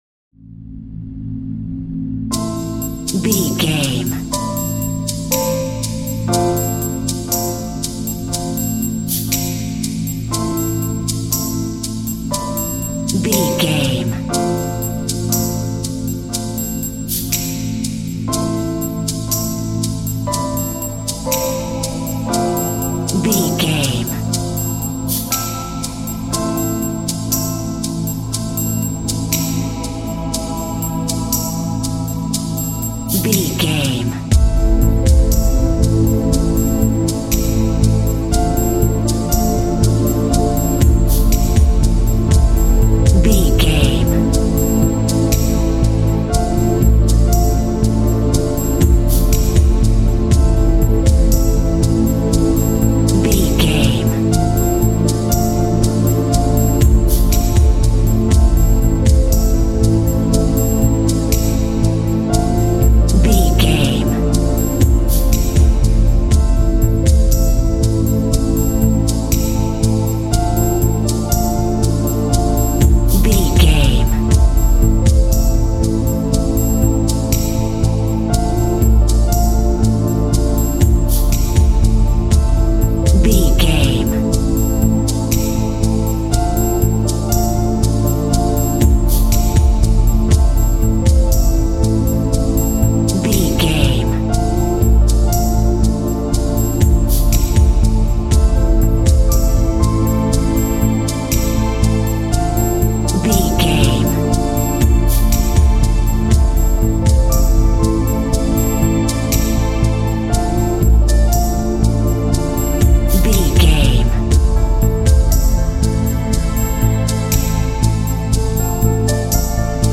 Thriller
Aeolian/Minor
Slow
synthesiser
piano
percussion
drum machine
tension
ominous
dark
suspense
haunting
creepy